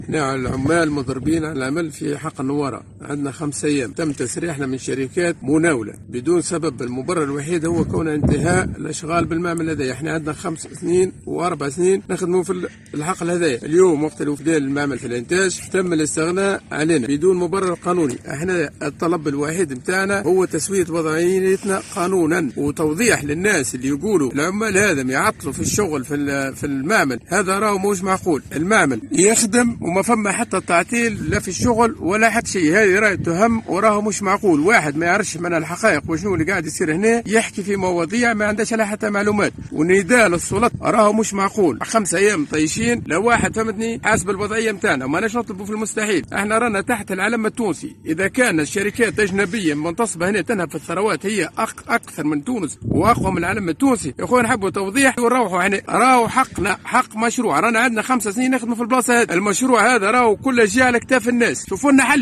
احد العمال يتحدث لمراسلنا